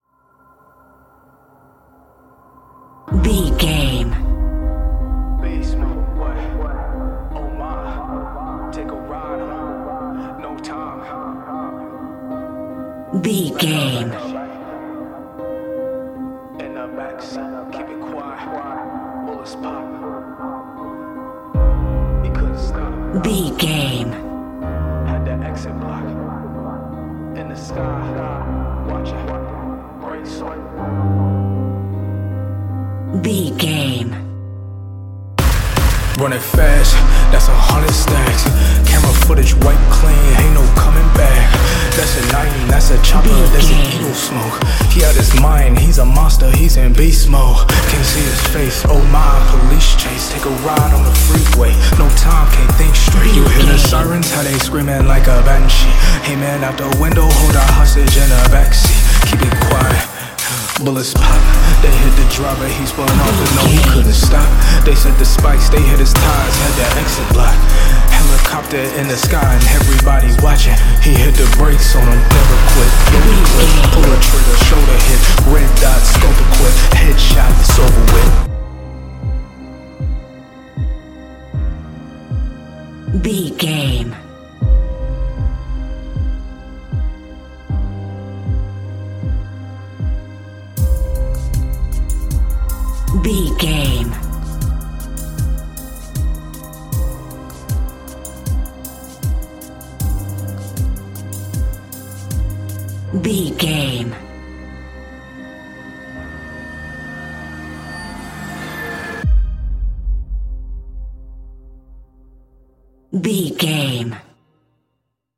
Ionian/Major
aggressive
heavy
frantic
dramatic
bass guitar
synthesiser
drum machine
piano
strings
vocals